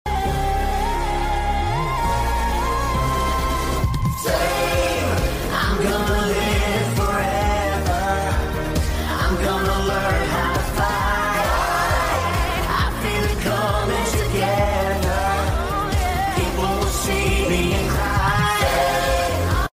Also yes these are the actual extracted veneer vocals!